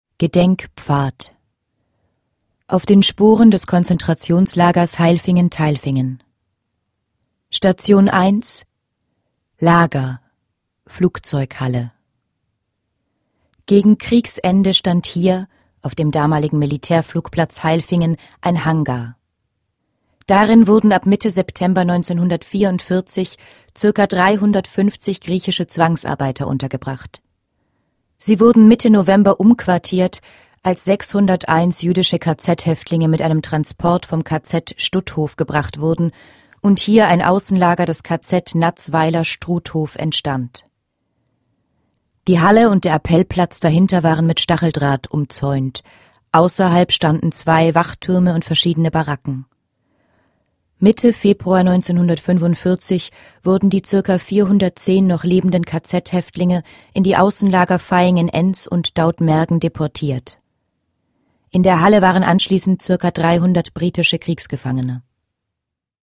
Audioguide Basisinfo
niedrige Audioqualität